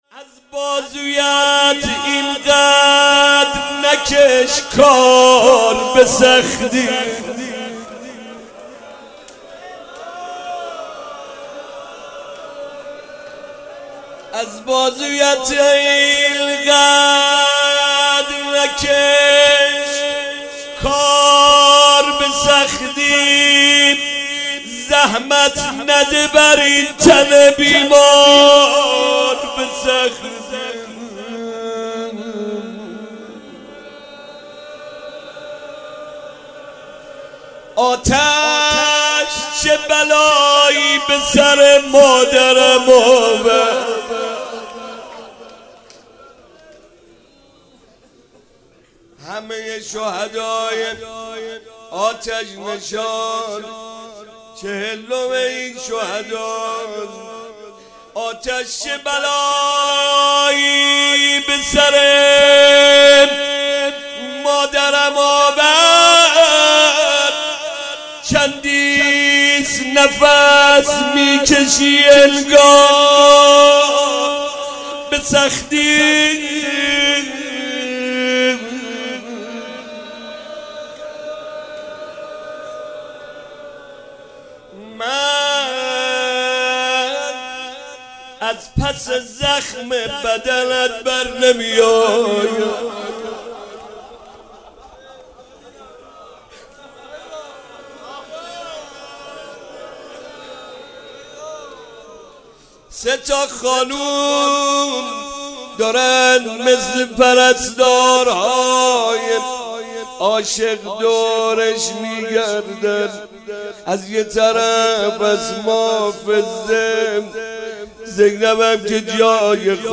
مداحی و نوحه
روضه خوانی
به مناسبت ایام فاطمیه
[روضه]